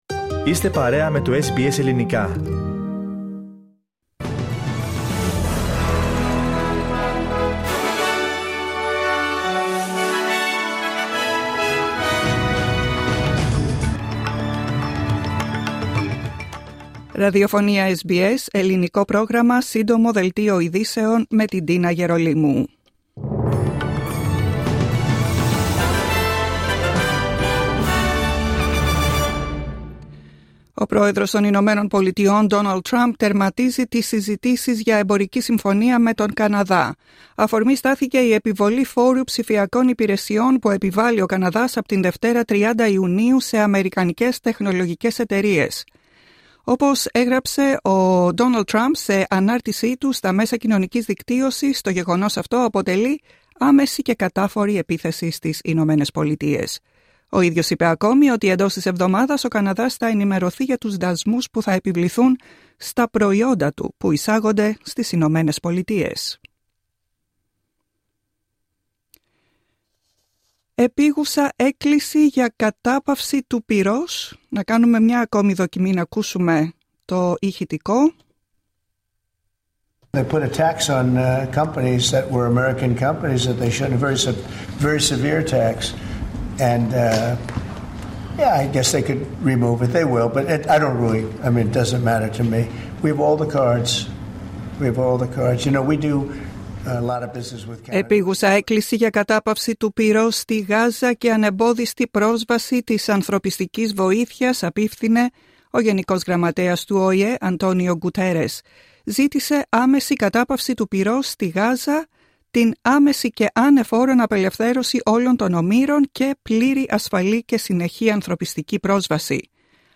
Σύντομο δελτίο ειδήσεων απ΄το Ελληνικό Πρόγραμμα της SBS.